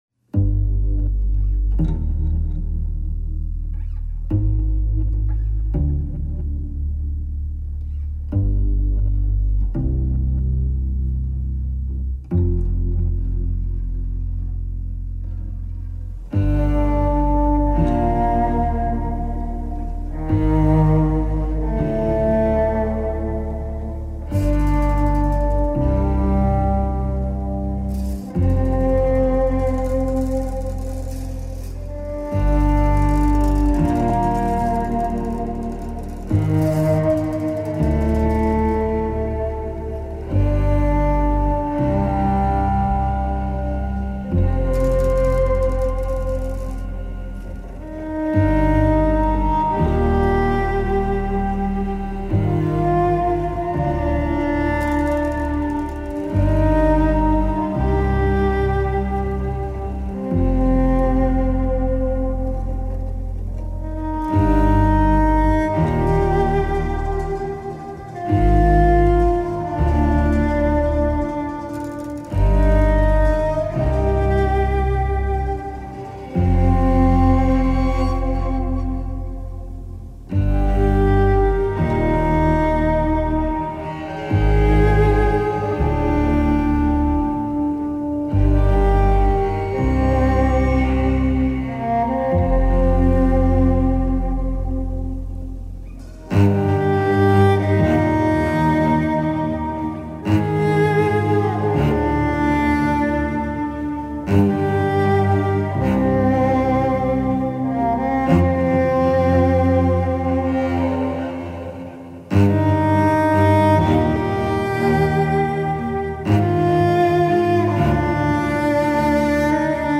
نمونه صدای کنترل باس
این ساز صدایی بسیار تیره وحجیم دارد و در مقایسه با دیگر ساز های زهی سرعت کمتر در نواختن نت ها دارد.